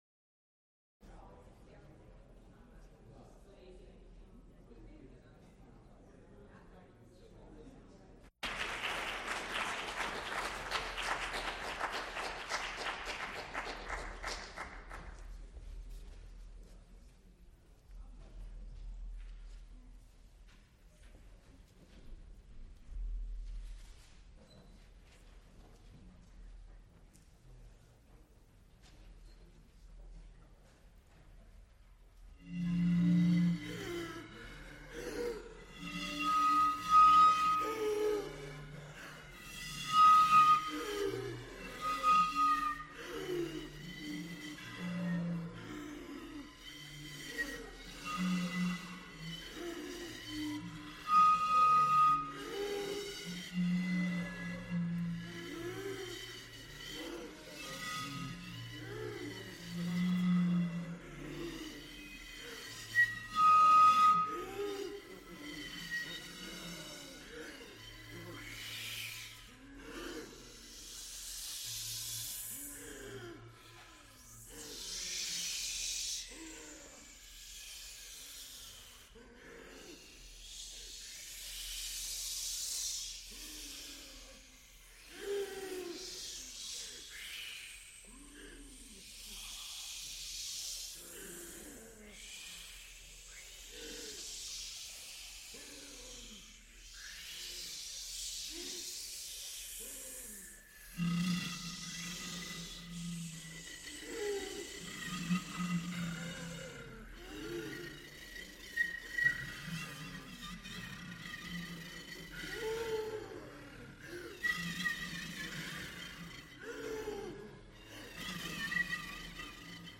for oboe, horn, violin and double bass
for violoncello and piano
Recorded live October 25, 1979, Frick Fine Arts Auditoruium, University of Pittsburgh.